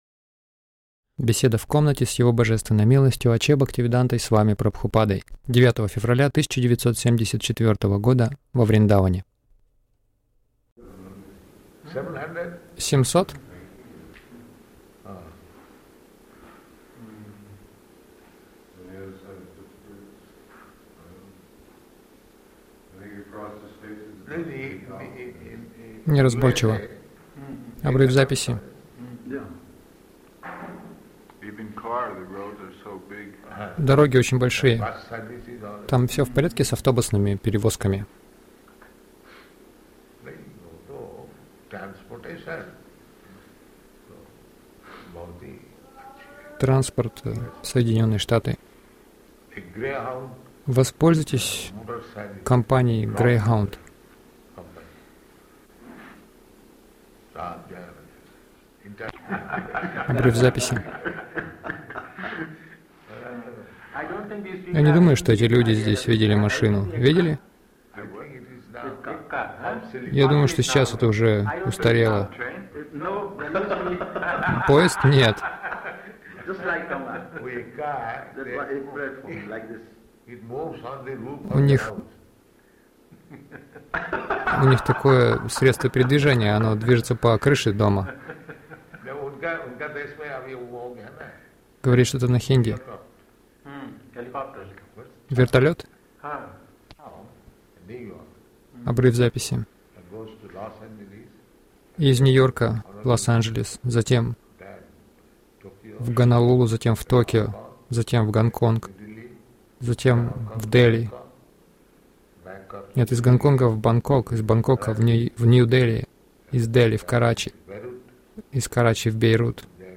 Беседа — Стройка. Шрила Прабхупада — Лучший отец